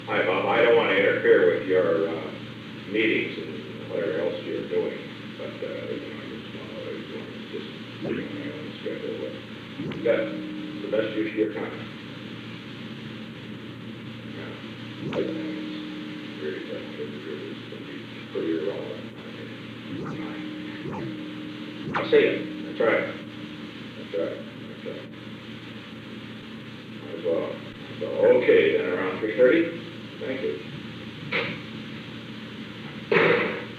Secret White House Tapes
Conversation No. 431-1
Location: Executive Office Building
The President talked with H. R. (“Bob”) Haldeman.